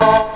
54_13_organ-A.wav